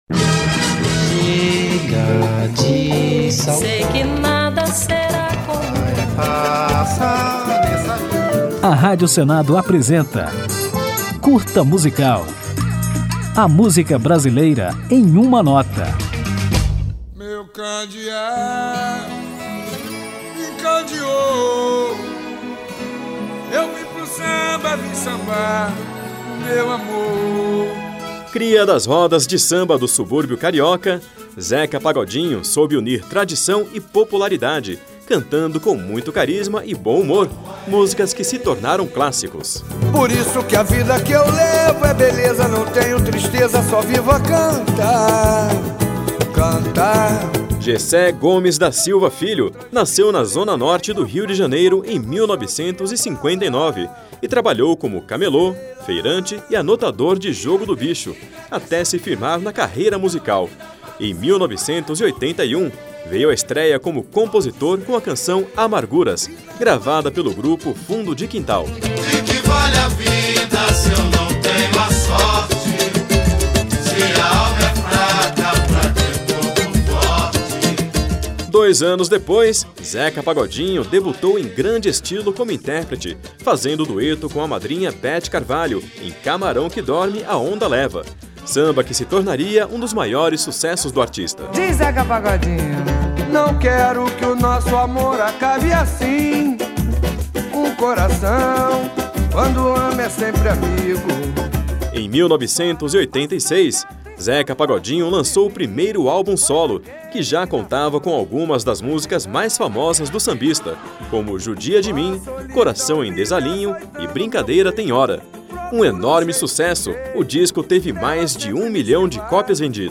Um deles é a música Deixa a Vida Me Levar, que ouviremos ao final do programa, com Zeca Pagodinho.
Samba